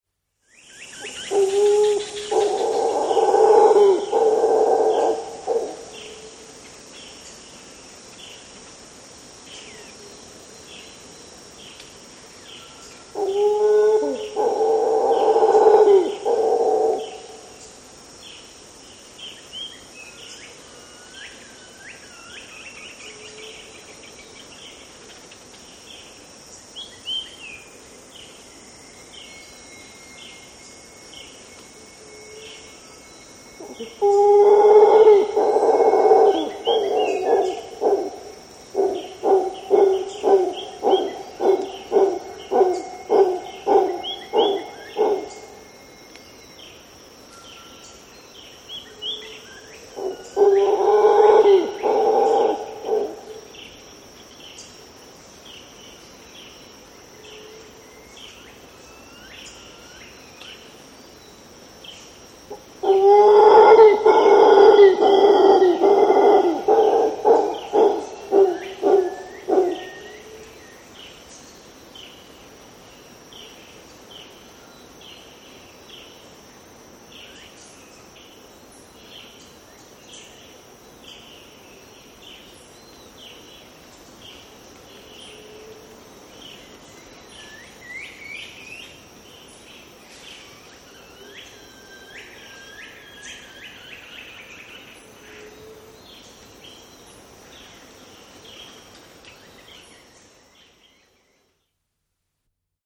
Звук колумбийского ревуна, записанный в Коста-Рике